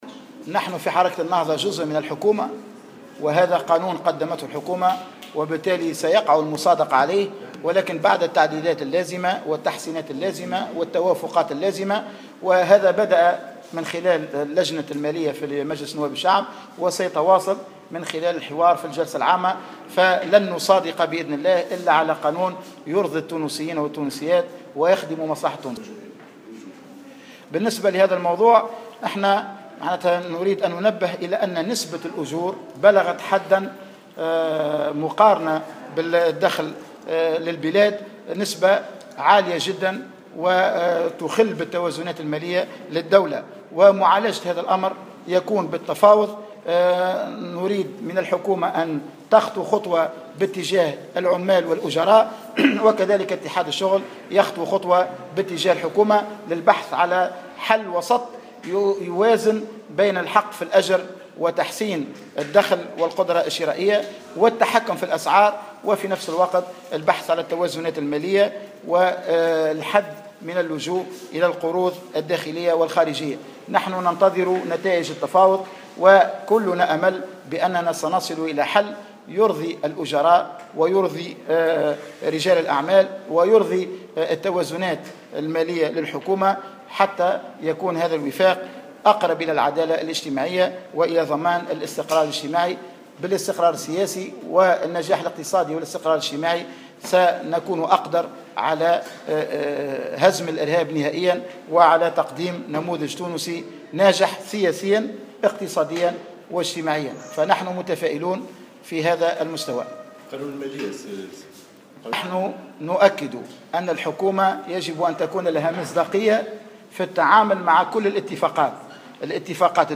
وجاء ذلك خلال اللقاء الاعلامي الذي انتظم في اختتام أعمال الدورة السابعة لمجلس شورى حركة النهضة.